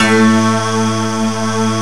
PAD 1990 1.wav